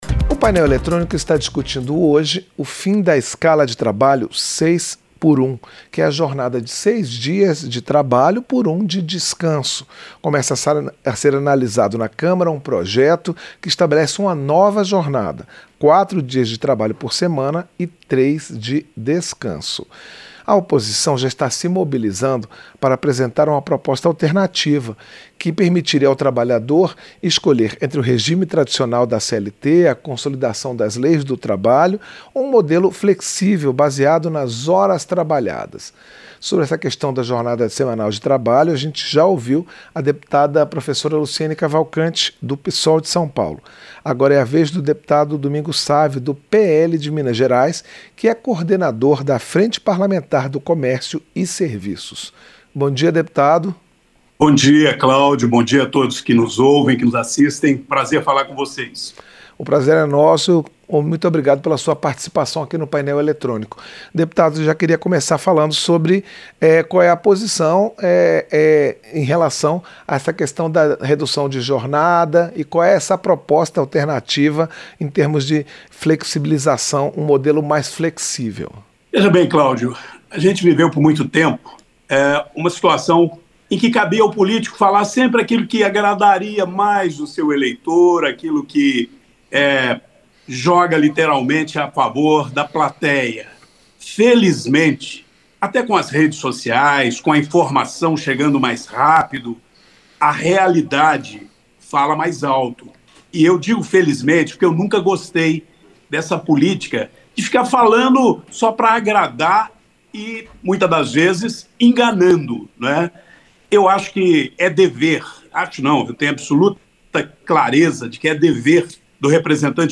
Entrevista - Dep. Domingos Sávio (PL-MG)